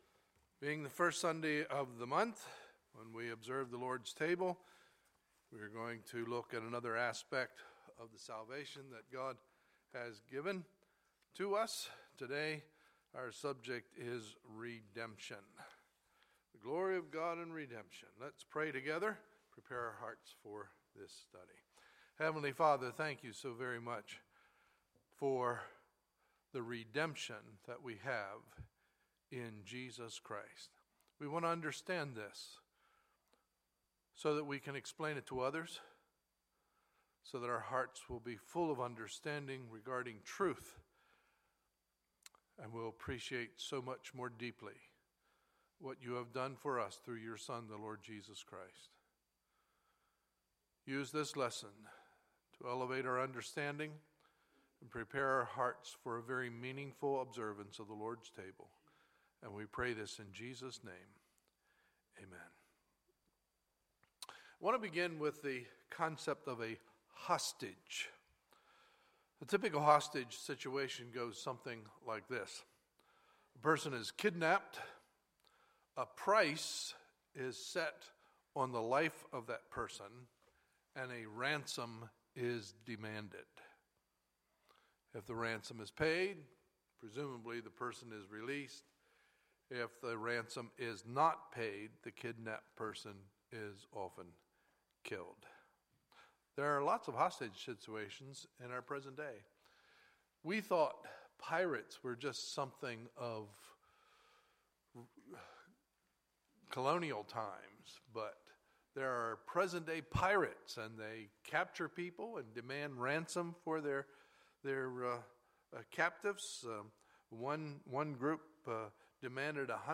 Sunday, April 3, 2016 – Sunday Morning Service